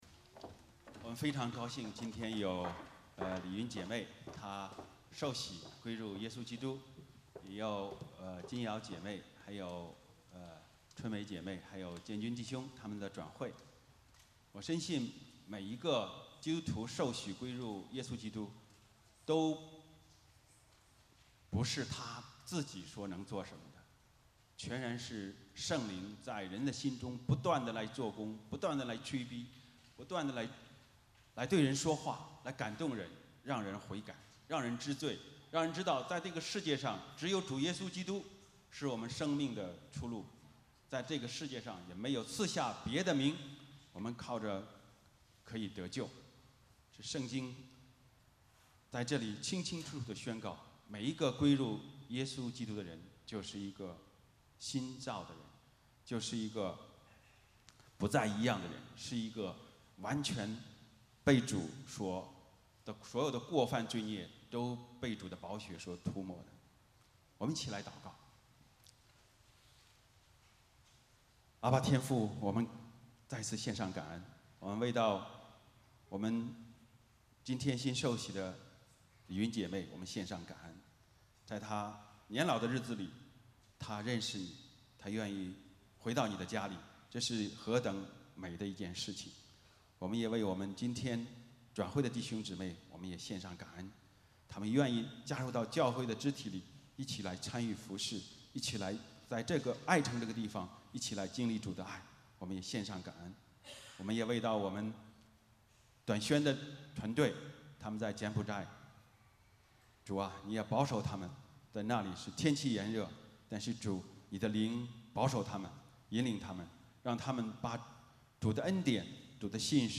Passage: 罗马书6:3-5 Service Type: 主日崇拜 欢迎大家加入我们的敬拜。